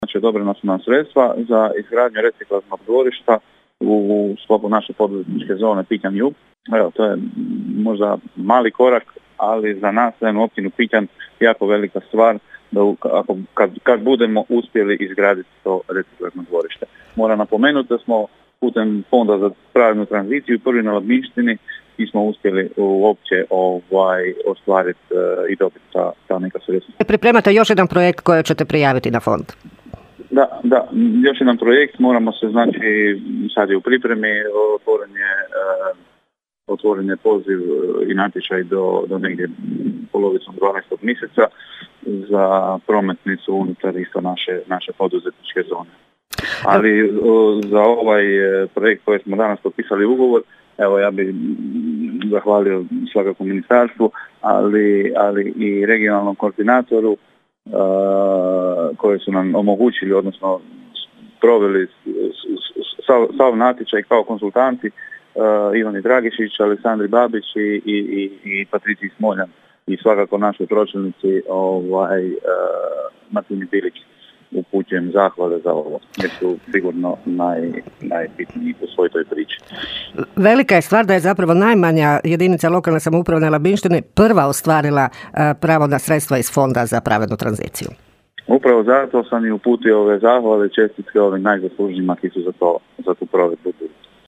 ton – Dean Močinić), rekao nam je općinski načelnik Pićna Dean Močinić.